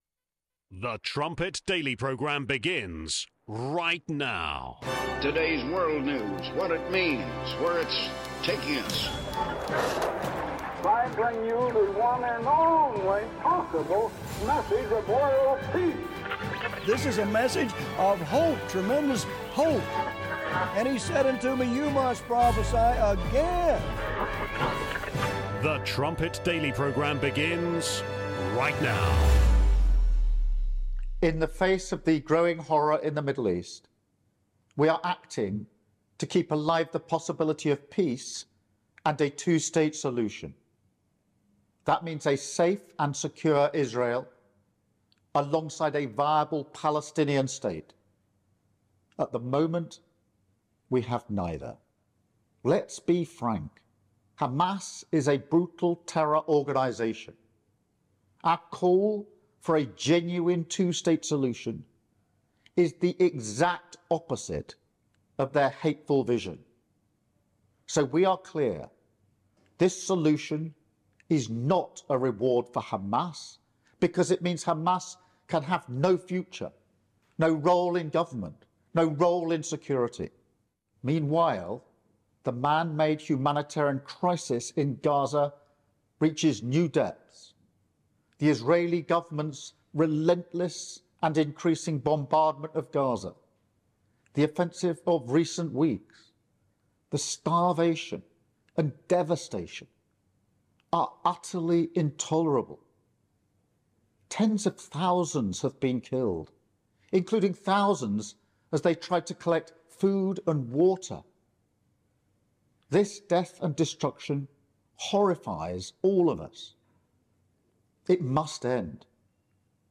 In this segment, we play 30 minutes of highlights from his last message to the Church.